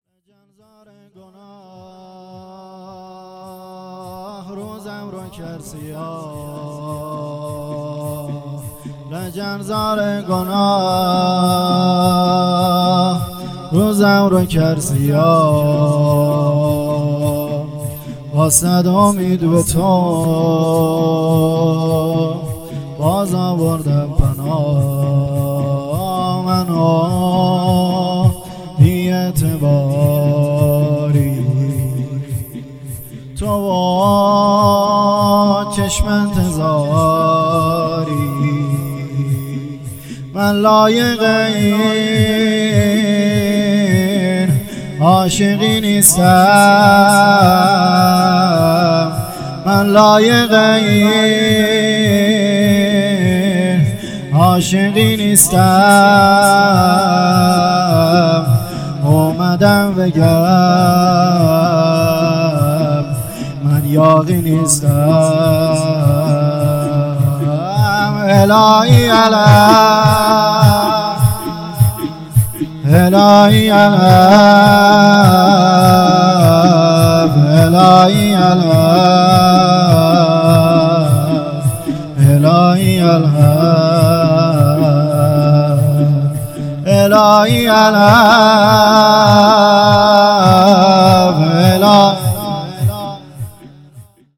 شهادت امام علی (ع) | هیئت میثاق با شهدا
شب اول شهادت امام علی (ع) | هیئت میثاق با شهدا